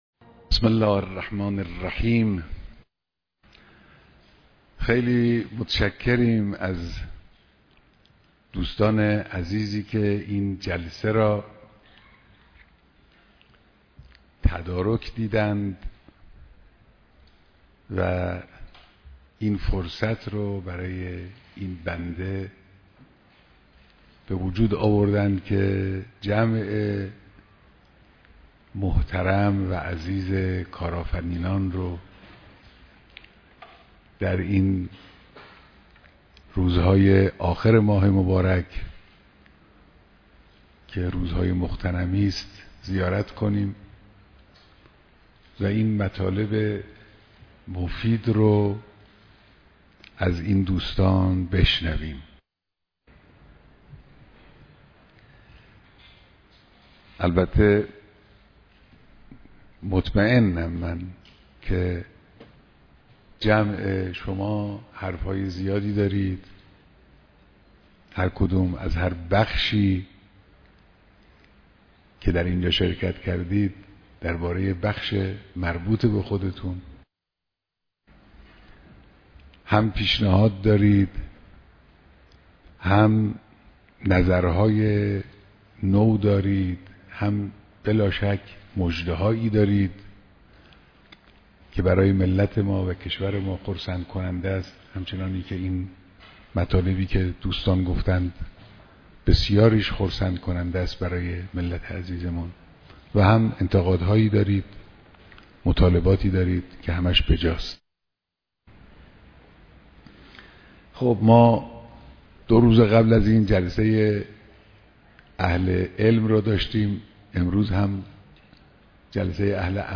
دیدار صدها نفر از کارآفرینان